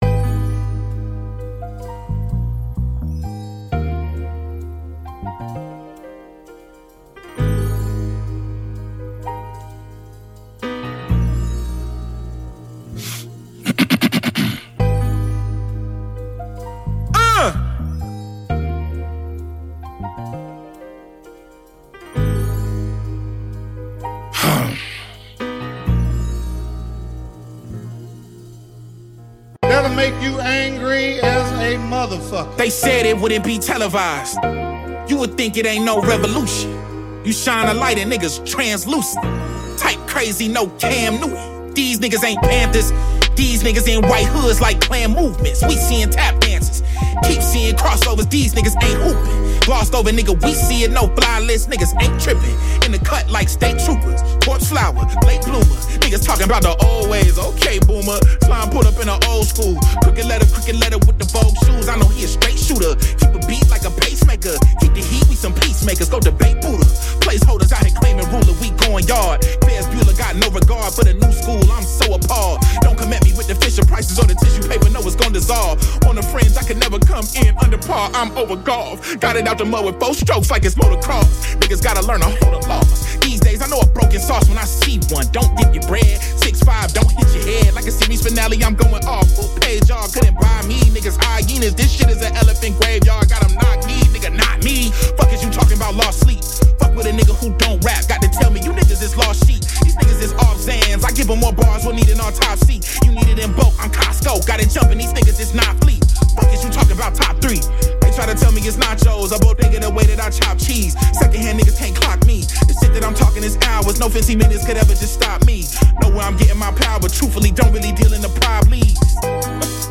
150 BPM.
Music / Rap
hip hop rap instrumental piano jazzy sample chops remix flip